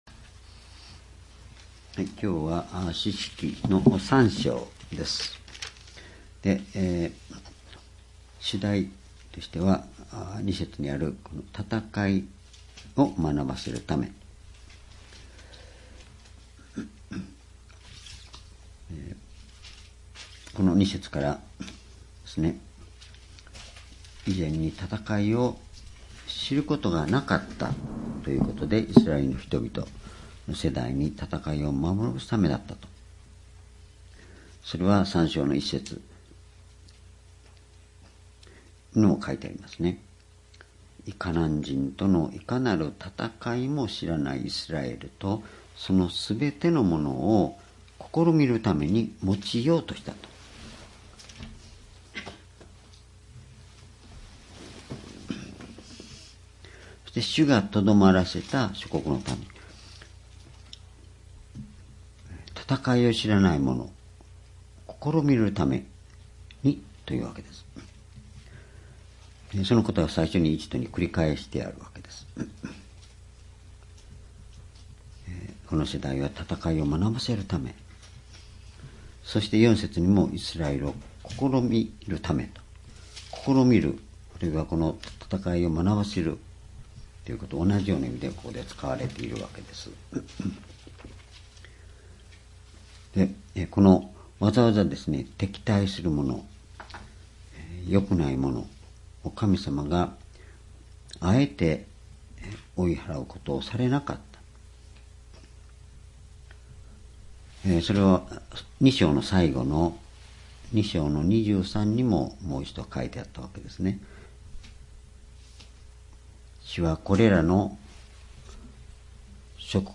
｢戦いを学ばせるため｣士師記3章 2019年10月15日 夕拝